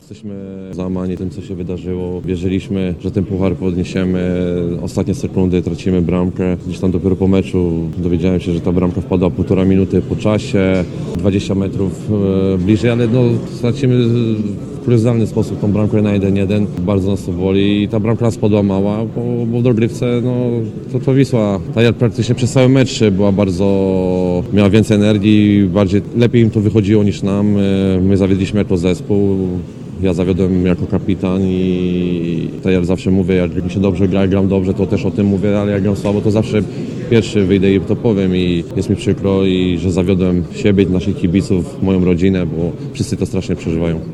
przyznał kapitan „Dumy Pomorza”, Kamil Grosicki.